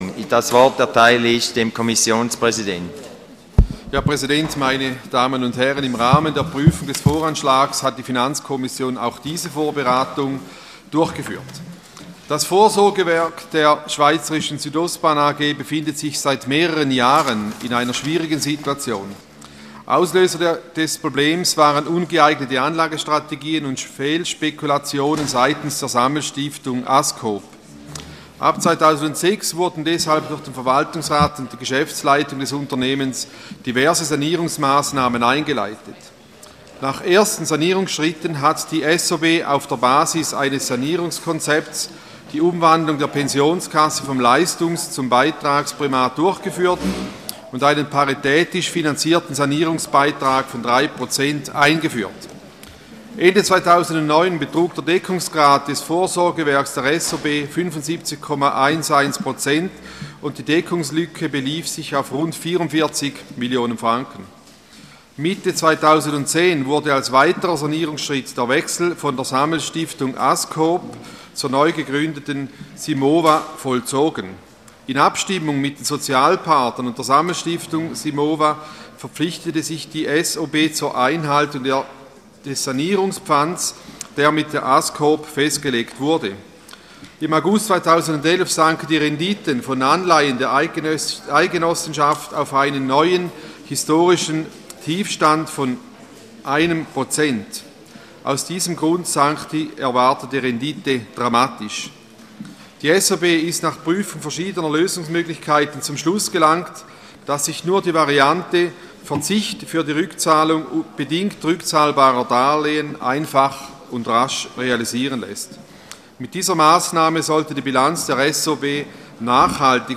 26.11.2012Wortmeldung
Session des Kantonsrates vom 26. bis 28. November 2012